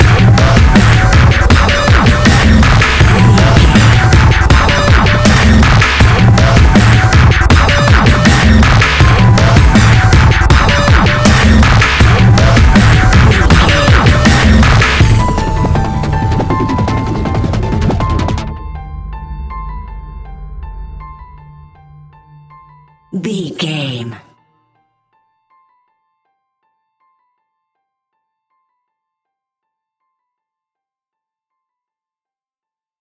Aeolian/Minor
Fast
aggressive
dark
industrial
heavy
drum machine
synthesiser
piano
breakbeat
energetic
synth leads
synth bass